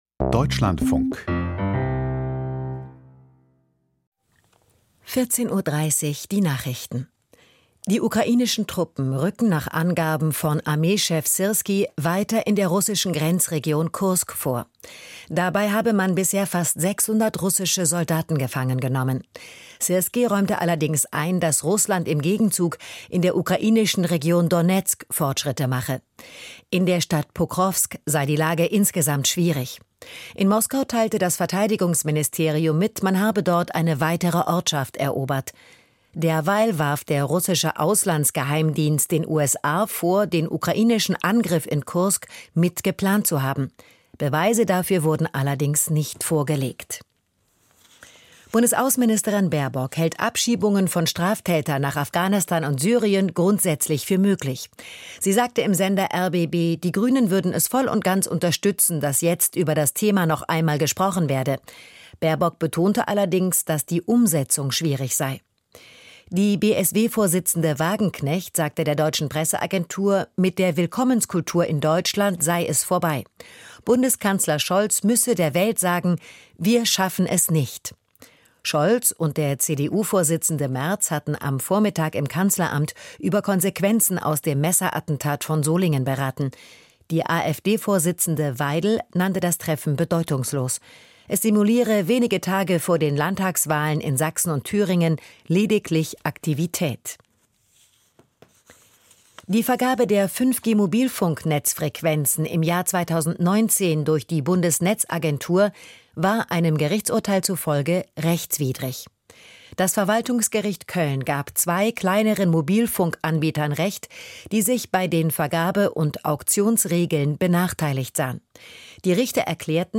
Die Deutschlandfunk-Nachrichten vom 27.08.2024, 14:30 Uhr